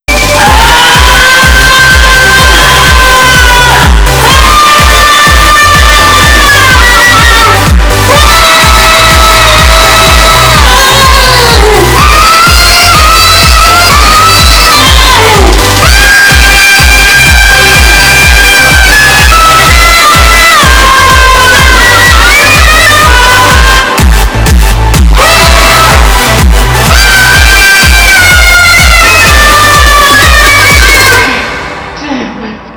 applause54.wav